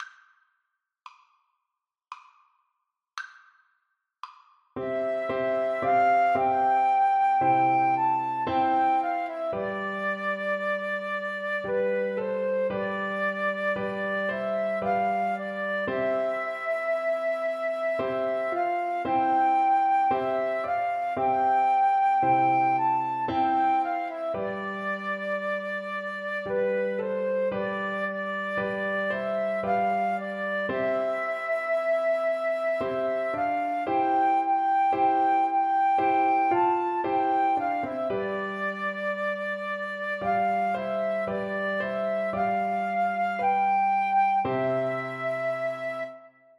3/4 (View more 3/4 Music)
Christmas (View more Christmas Flute Music)